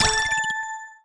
Trade Greenlight Final Sound Effect